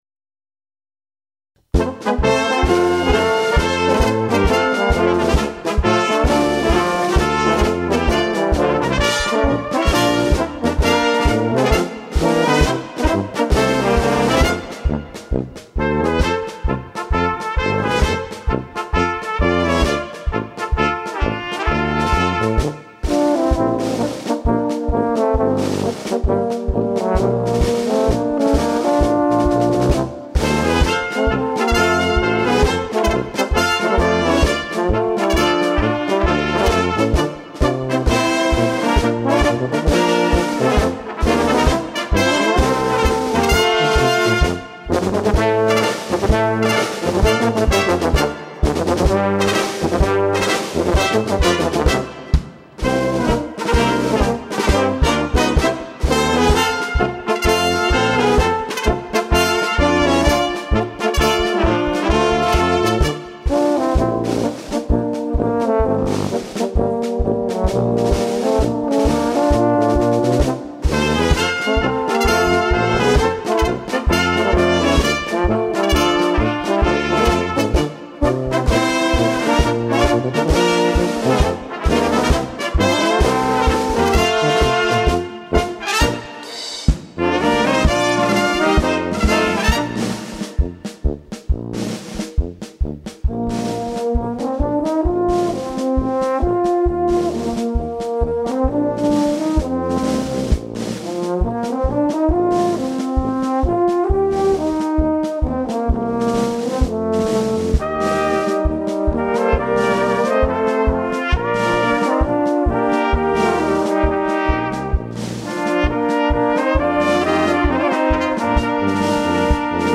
Gattung: Marsch
Besetzung: Blasorchester